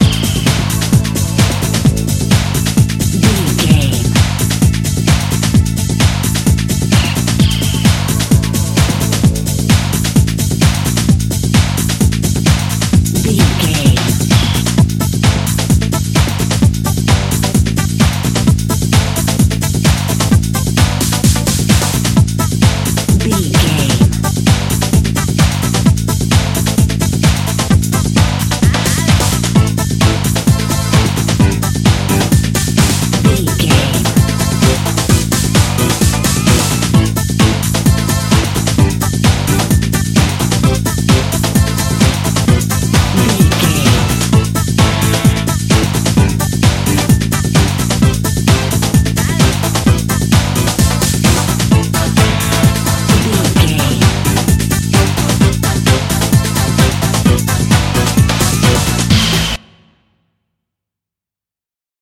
Aeolian/Minor
synthesiser
drum machine
Eurodance